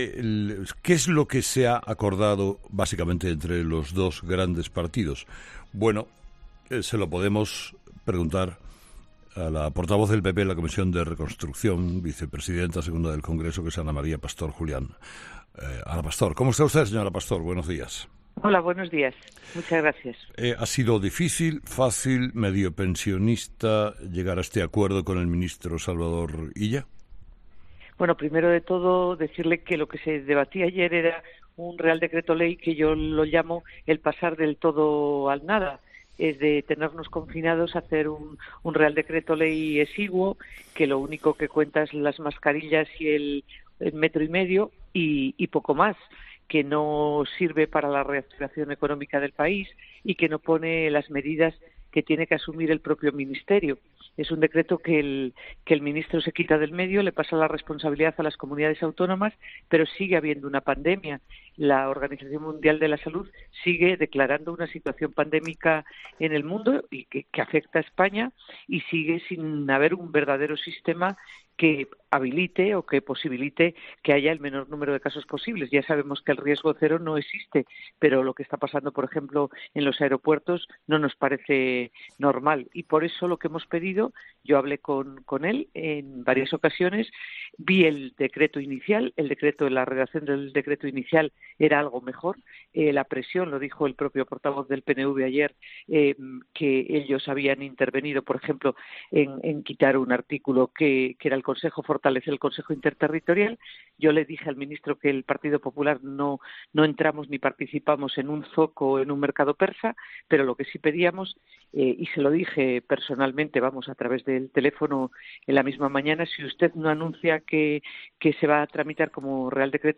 La diputada del PP Ana Pastor habla sobre el decreto de nueva normalidad e incide en la importancia de mantener controles en los aeropuertos